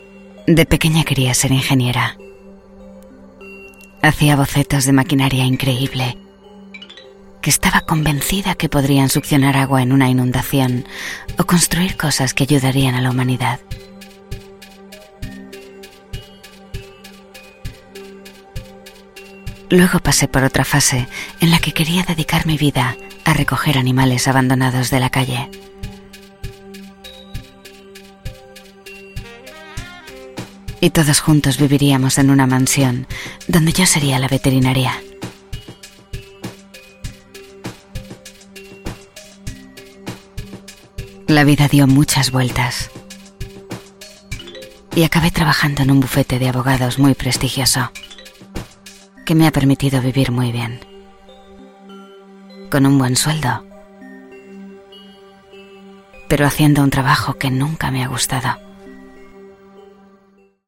Movie Trailers
Castilian Spanish online voice over artist fluent in English.
Soundproof recording booth ( studiobricks)
Mic Neumann U87 Ai